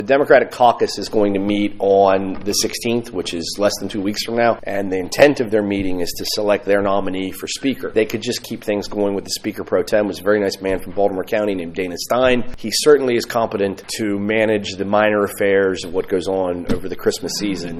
The Western Maryland Delegation to Annapolis held an overview of the coming legislative session at Rocky Gap on Friday.
Delegate Jason Buckel said the news of House Speaker Adrienne Jones stepping down would have the Democrats working overtime…